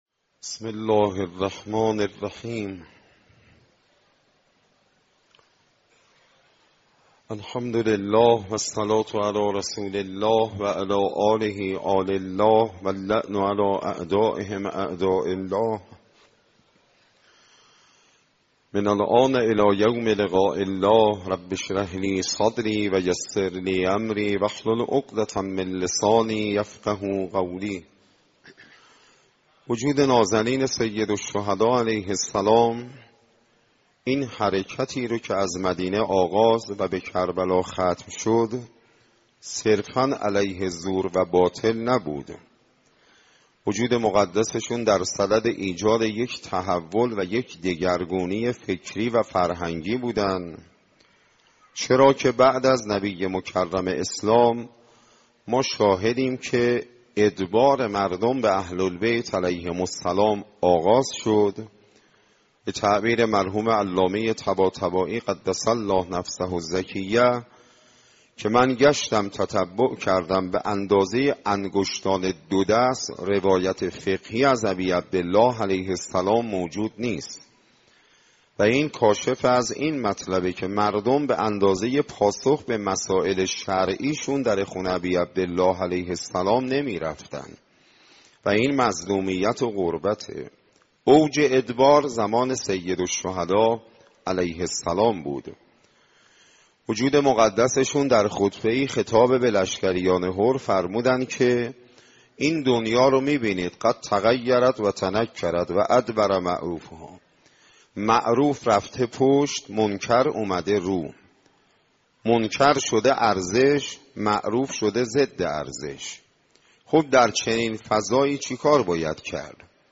سخنرانی ماه محرم